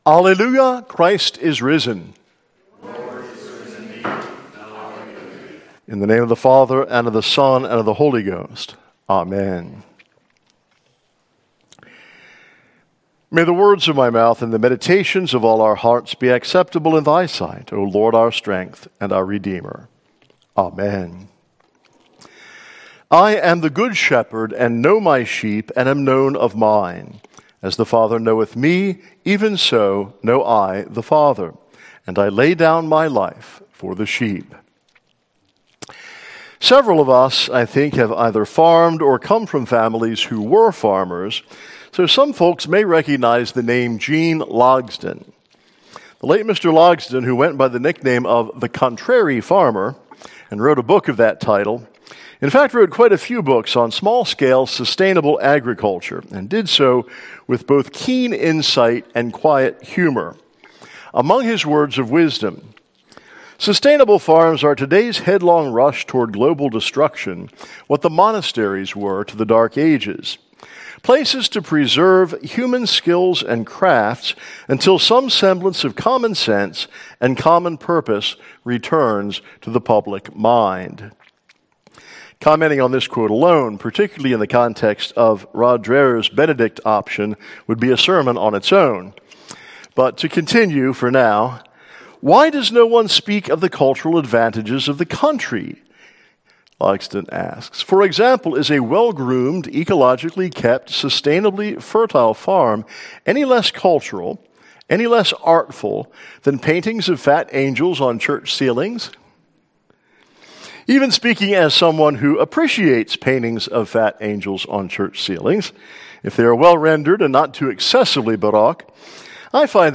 Listen to the sermon for the Second Sunday after Easter.